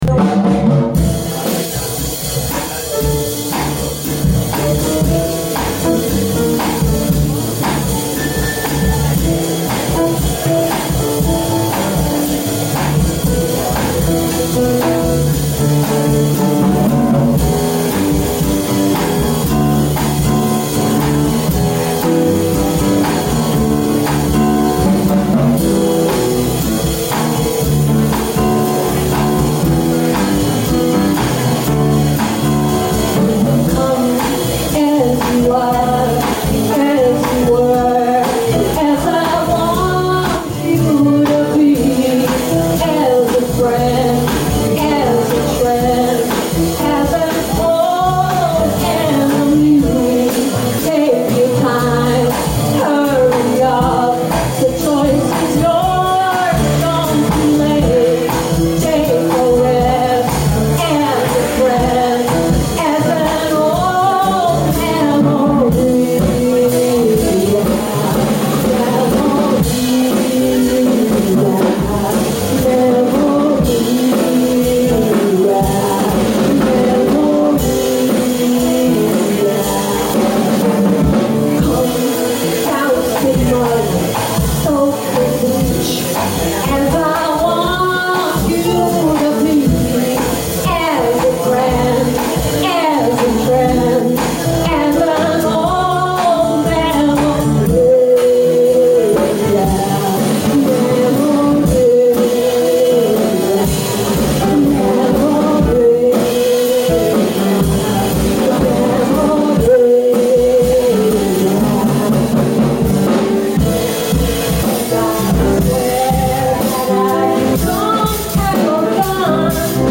Piano
Drums
Boston Bebop Jam